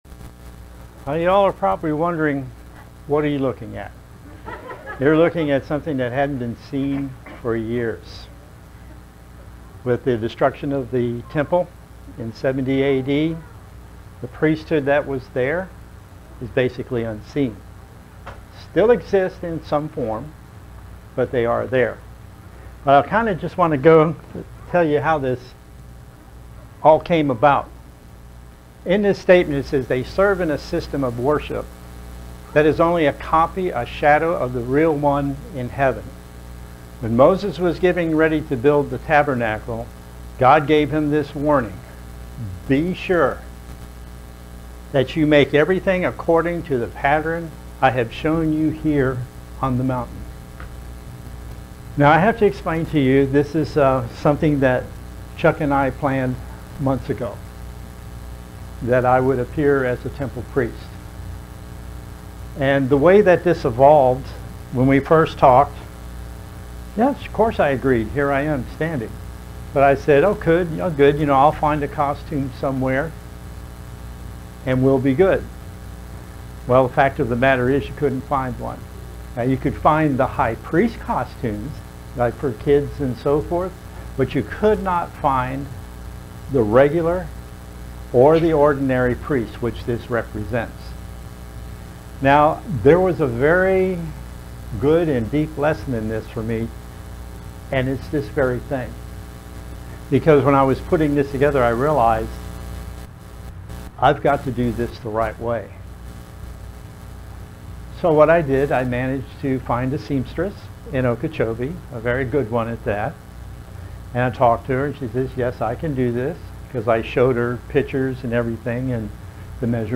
A brief history of the priesthood at God's tabernacle is told through authentic garments that were worn by some priests during that time. (There is a slight sound distortion in the first few minutes of this video)
Given in Vero Beach, FL